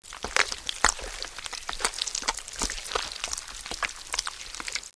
Ghoul_Consuming.wav